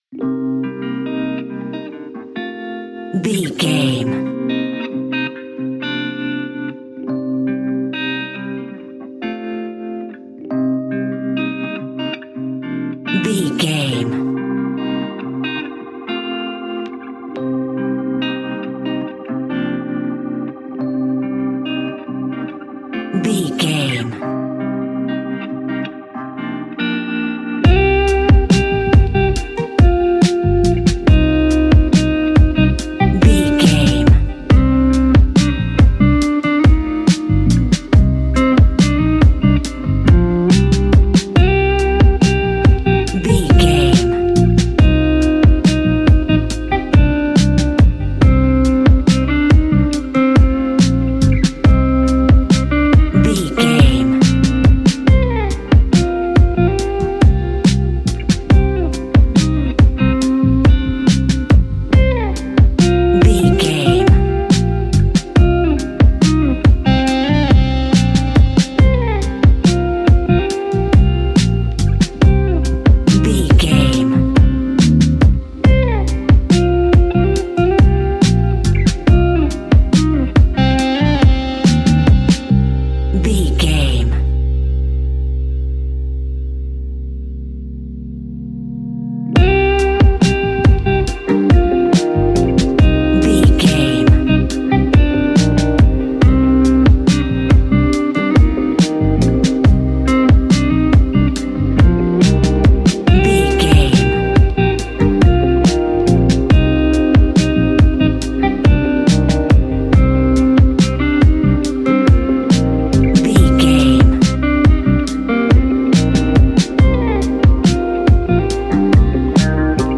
Ionian/Major
C♭
chilled
laid back
sparse
chilled electronica
ambient
atmospheric